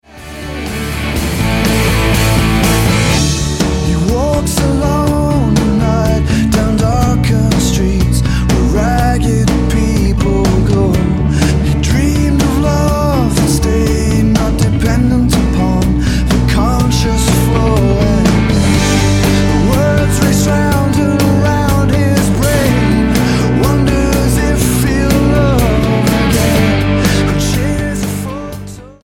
blues rockers